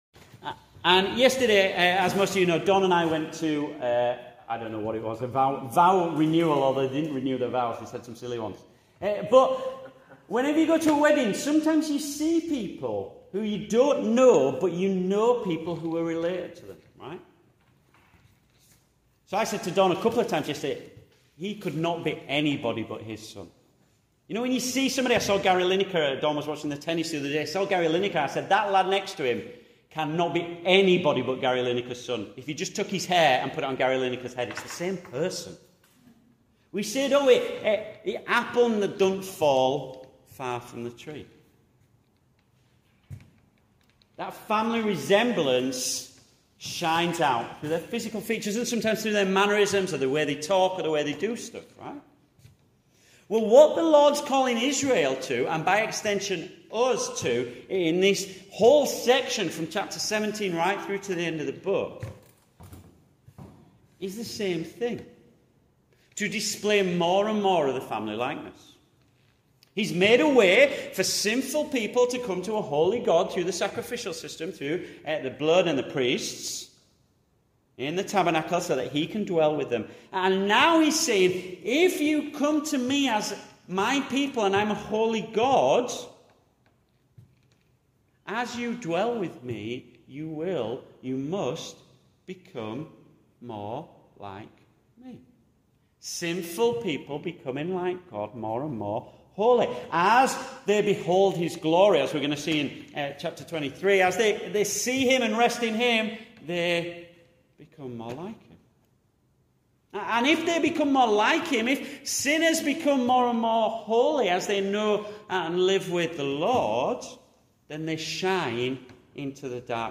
Returning Home Passage: Leviticus 19-20 Service Type: Morning Service « Who has not appealed to what is false Who may stand in his holy place?